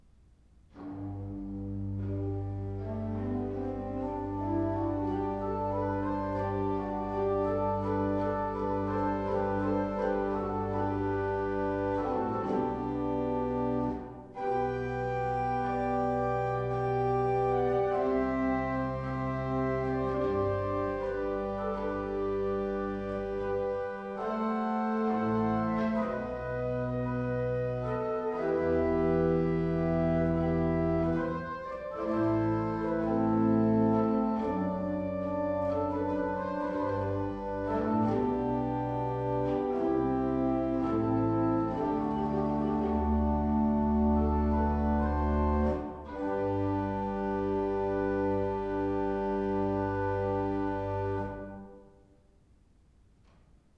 1787 Tannenberg Organ
To listen to an improvisation on all four 8' stops with the Sub: Bass 16' and Octav Bass 8" with the Koppel', click
Improvisation_All_8_foot_stops_mit_pedal.wav